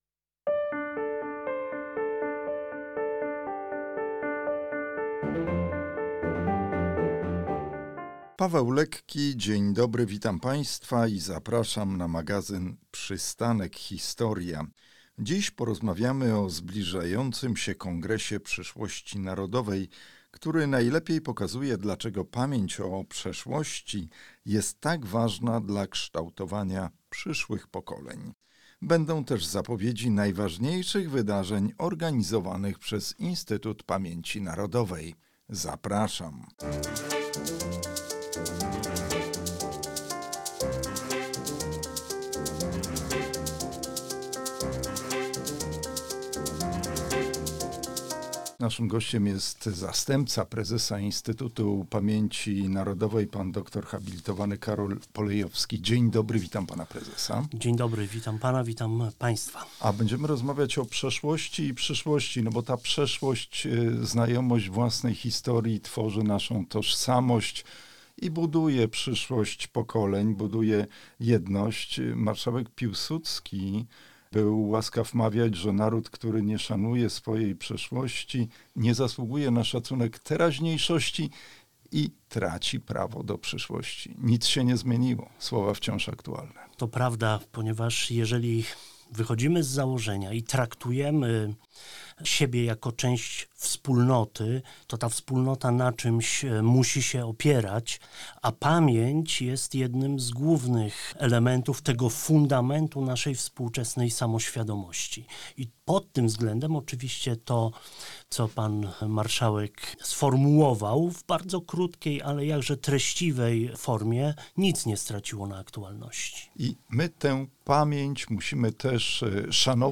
O nadchodzącym Kongresie Przyszłości Narodowej rozmawiamy z zastępcą prezesa Instytutu Pamięci Narodowej drem hab. Karolem Polejowskim.